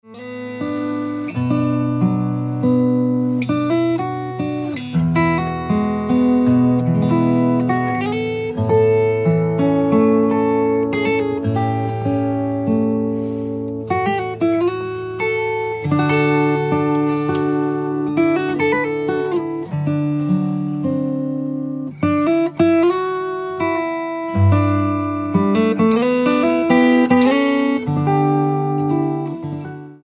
Traditional hymn instrumentals for guitar, violin and flute